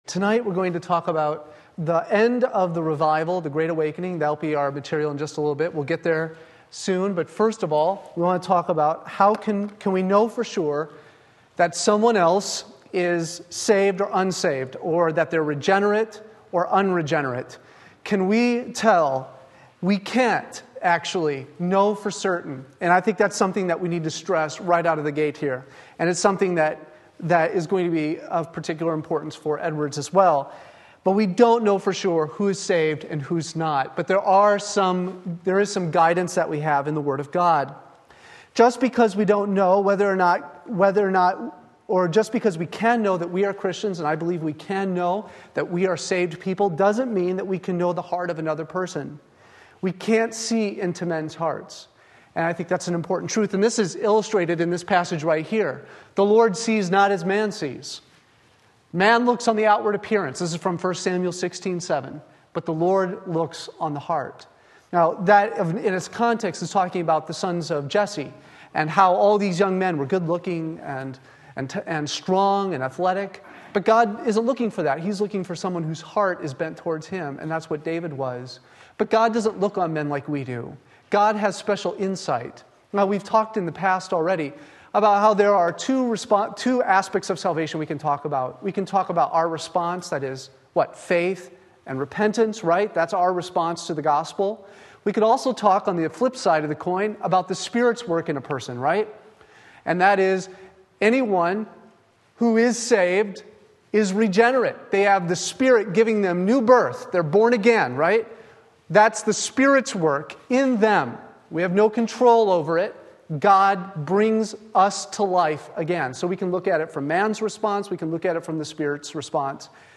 Sermon Link
Religious Affections Matthew 7:16 Wednesday Evening Service